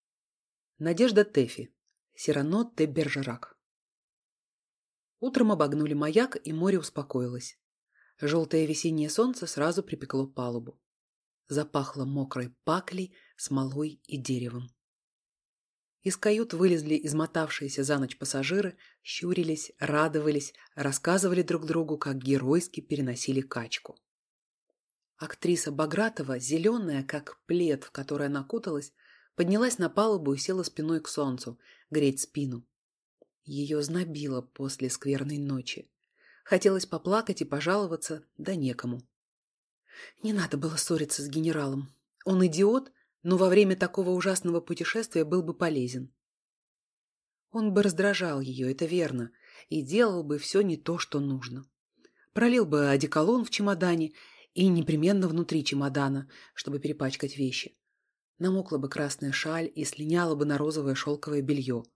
Аудиокнига Сирано де Бержерак | Библиотека аудиокниг